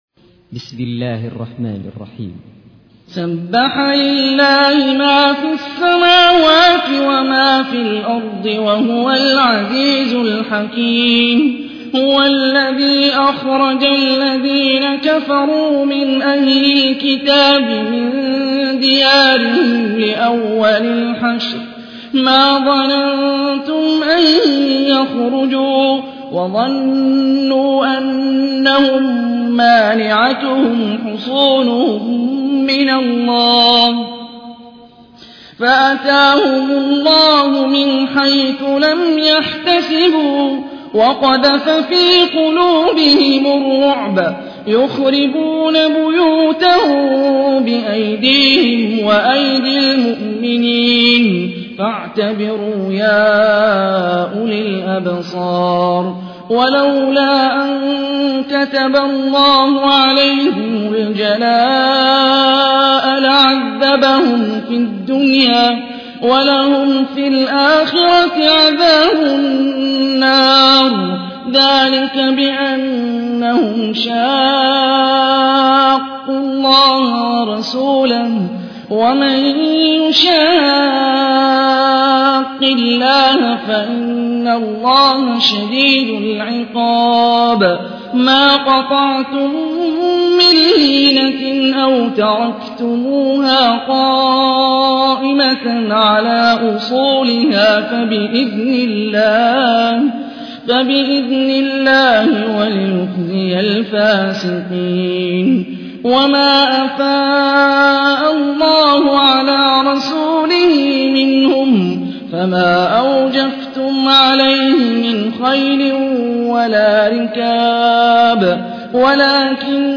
تحميل : 59. سورة الحشر / القارئ هاني الرفاعي / القرآن الكريم / موقع يا حسين